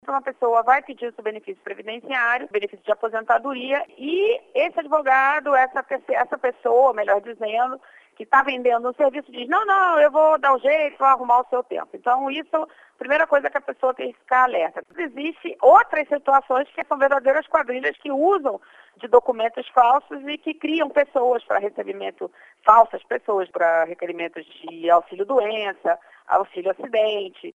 A subprocuradora-geral da república, Luiza Cristina Frischeisen, explica a ação das quadrilhas.